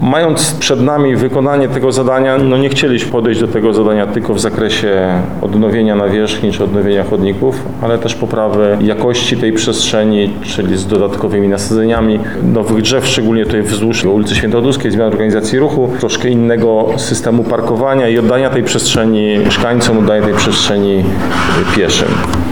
Artur Szymczyk– mówi Artur Szymczyk Zastępca Prezydenta Miasta Lublin